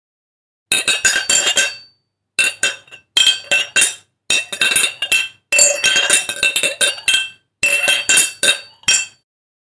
zvuk-tarelok_006.mp3